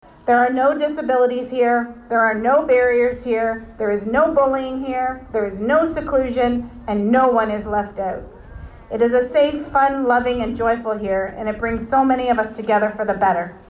It was sunshine and blue skies on a joyful Saturday as the Field of Ability at Parkdale Veterans Park in Belleville was officially opened.